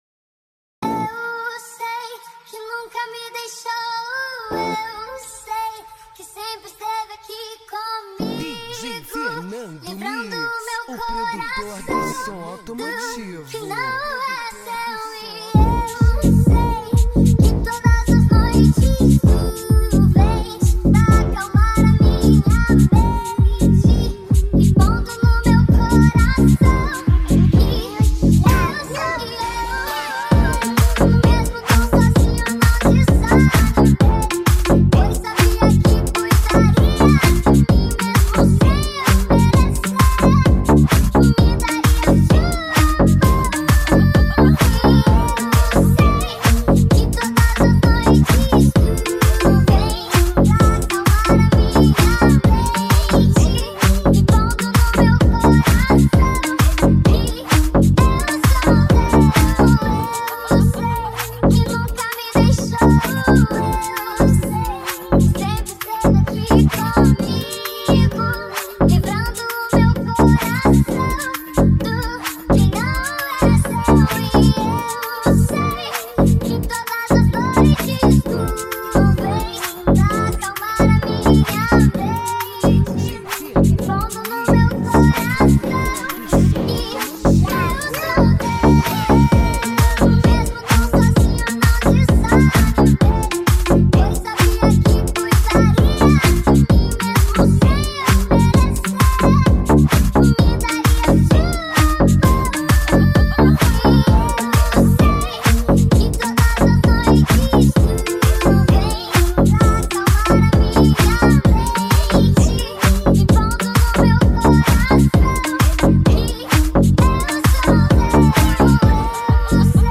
MTG GOSPEL MEGA FUNK
Remix